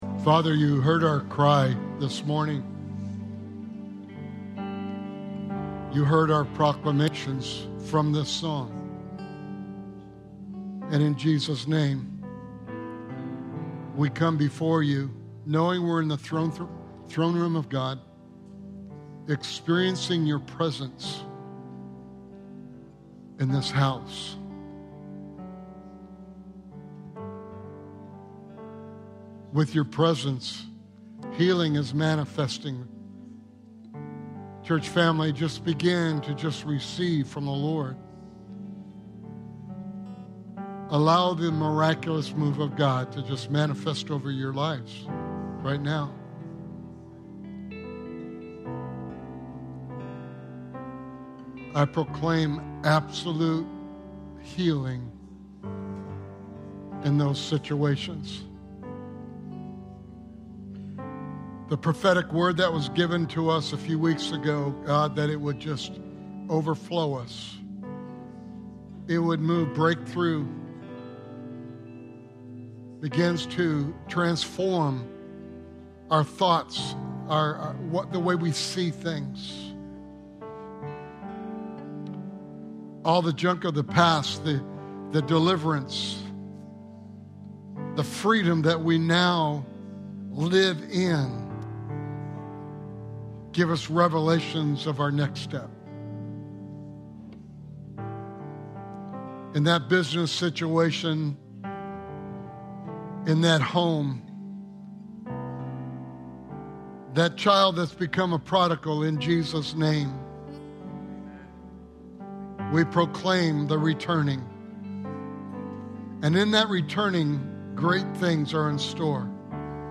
Sermon Series: The Parable of the Lost and Found